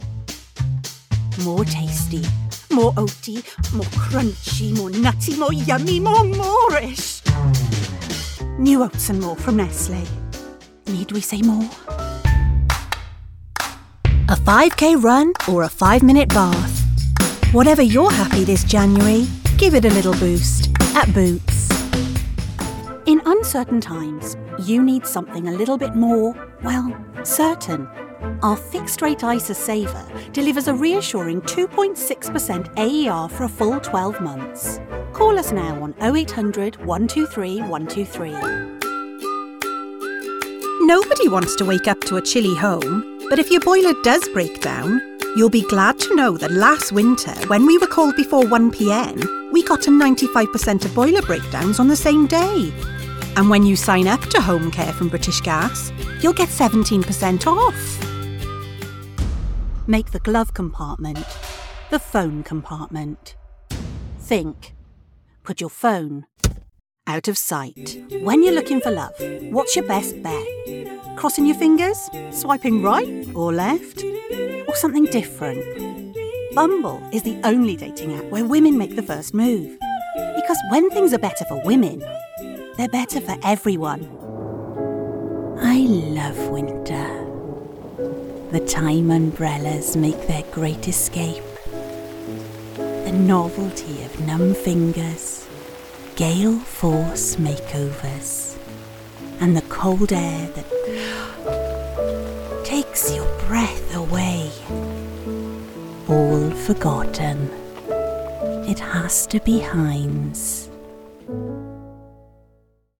Commercial Showreel
Female
Friendly
Warm